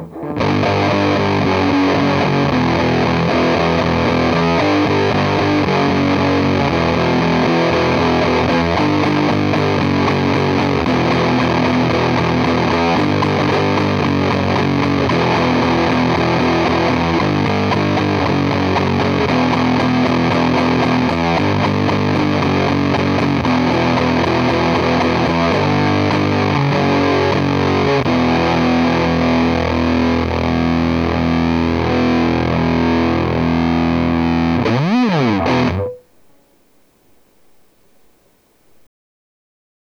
Faite gaffe je vais ressortir la fuzz face si ça continue